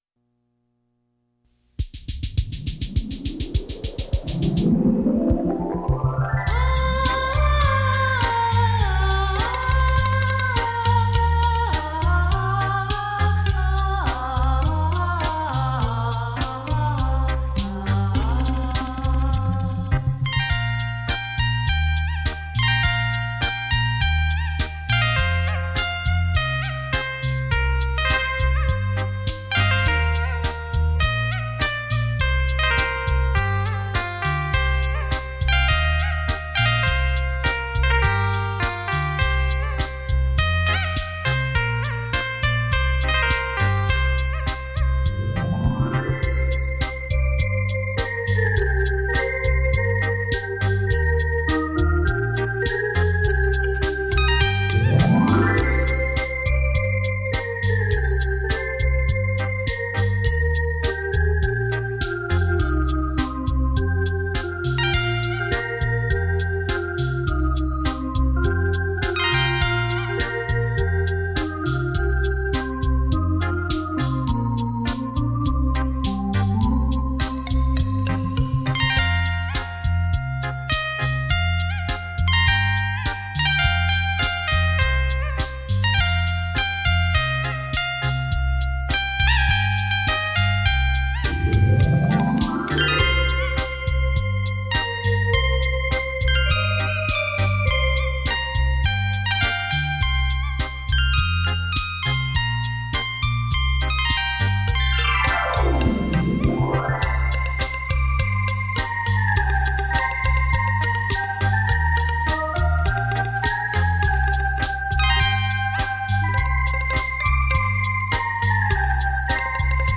怀念动人的音乐演奏
悠扬回荡